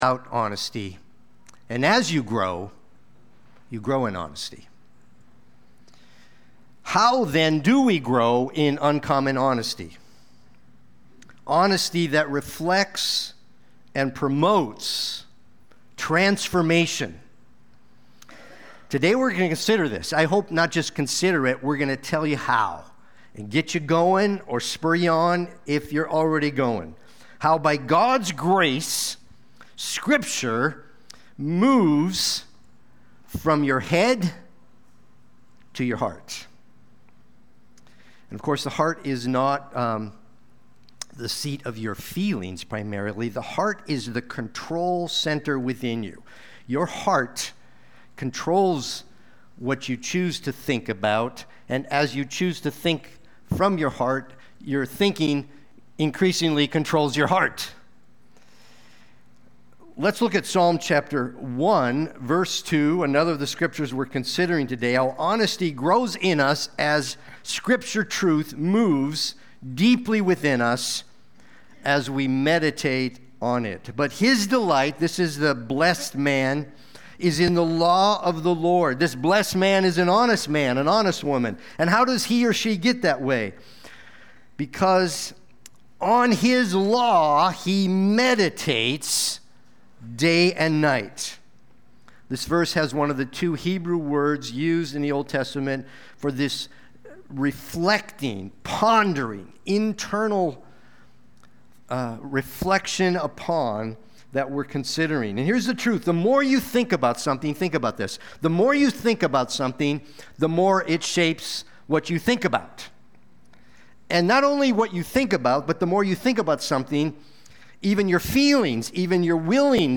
Psalm 19:11-14 & Psalm 119:11 Watch the replay or listen to the sermon.
Sunday-Worship-main-6825.mp3